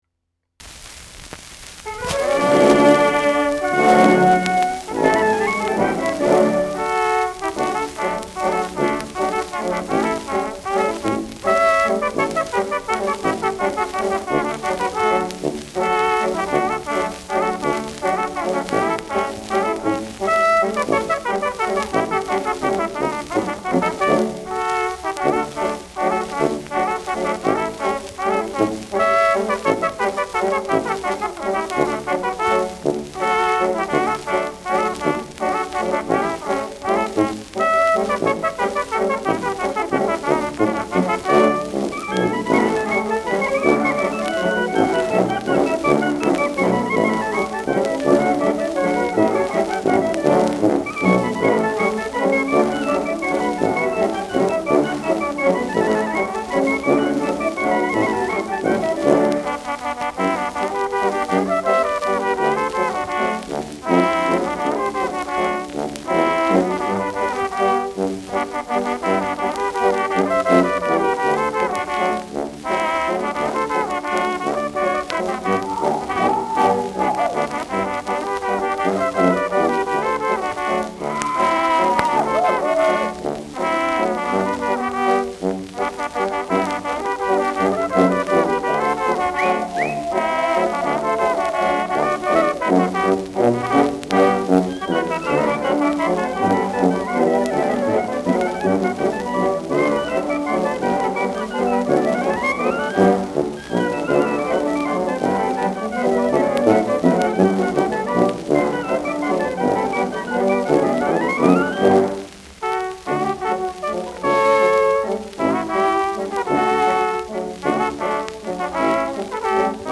Schellackplatte
abgespielt : präsentes Rauschen : präsentes Knistern : leiert : Nadelgeräusch
Mit Pfiffen und Juchzern.